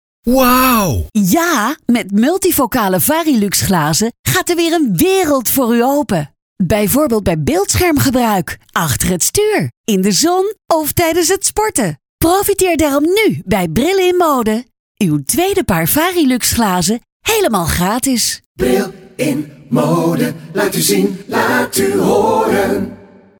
Hierboven valt ook de bijbehorende reclamespot voor de regionale radio te beluisteren.